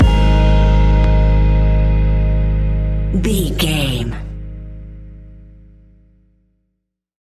Ionian/Major
G♭
chilled
laid back
Lounge
sparse
new age
chilled electronica
ambient
atmospheric